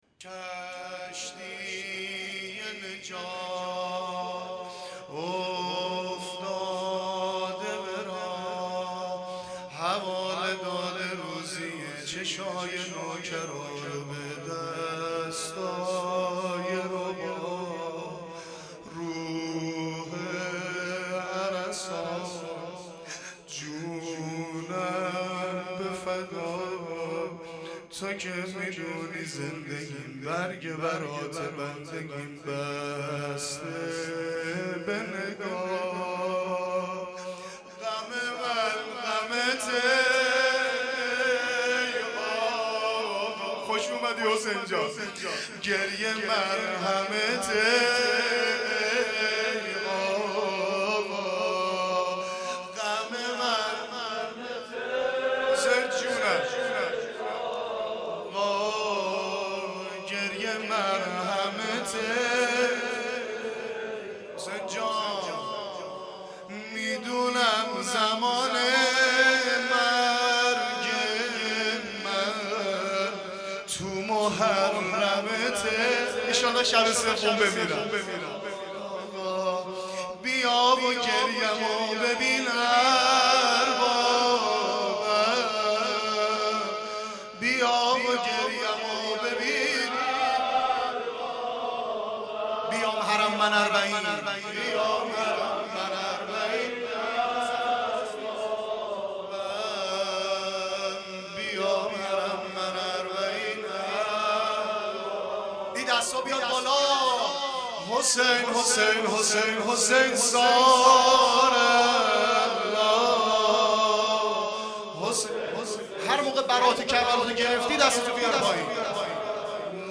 حسینیه توحیدی
شب دوم محرم الحرام 97